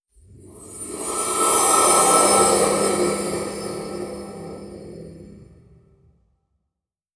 Efecto de cortinilla, pasada de cartón
Sonidos: Especiales
Sonidos: Fx web